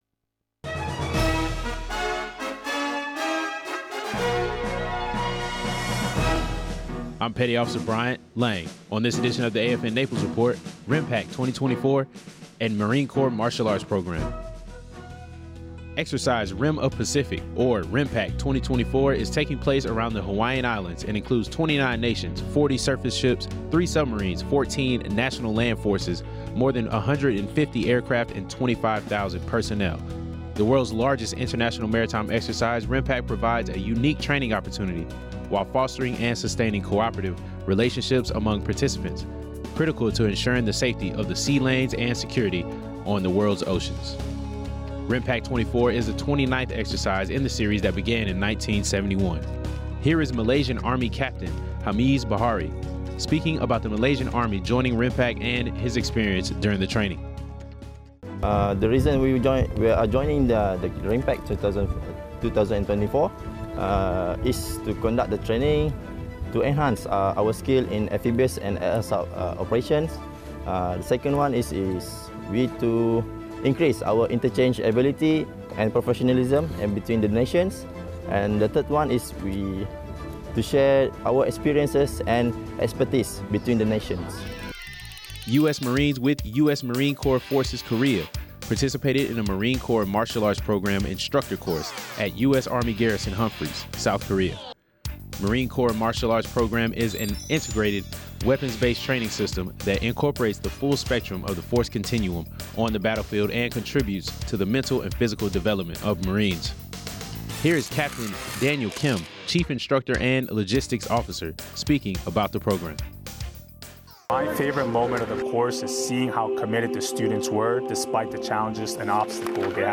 Regional news highlighting the Marine Corps Martial Arts Instructor class and RIMPAC 24.